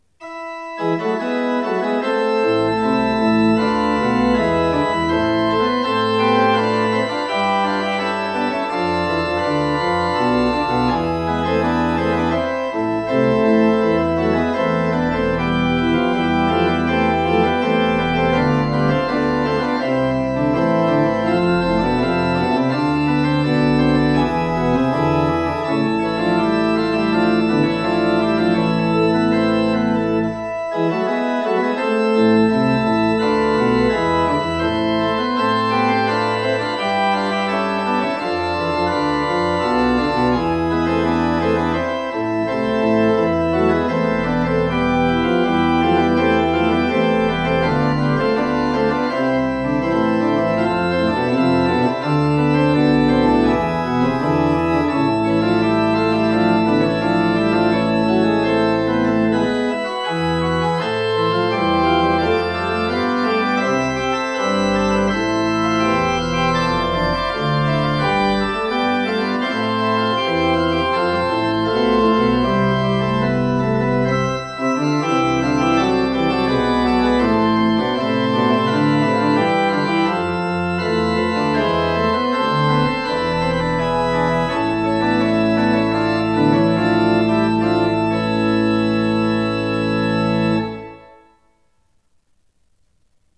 Orgelklang
an der Andresen-Orgel der Martin-Luther-Gemeinde Bad Schwartau
Orgelklang3_BWV642.mp3